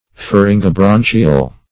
Meaning of pharyngobranchial. pharyngobranchial synonyms, pronunciation, spelling and more from Free Dictionary.
Search Result for " pharyngobranchial" : The Collaborative International Dictionary of English v.0.48: Pharyngobranchial \Pha*ryn`go*bran"chi*al\, a. [Pharynx + branchial.]